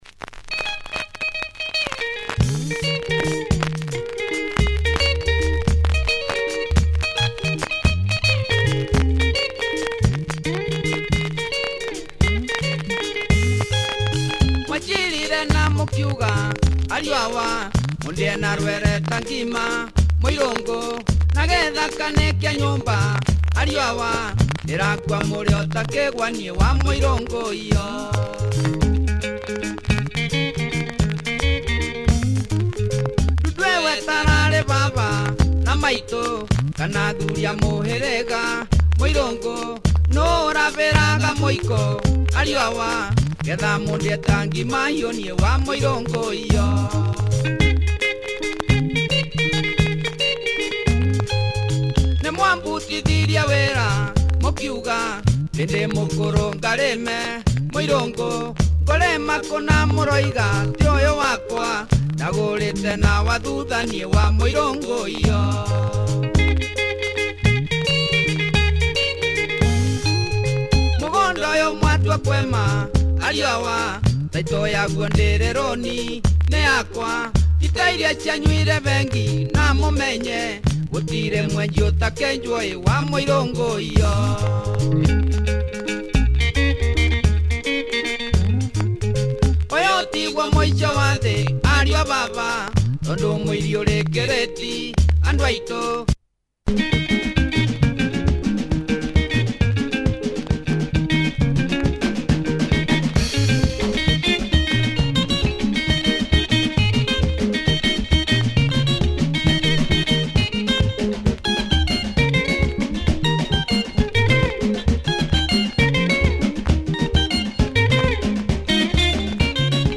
headnodding backbeat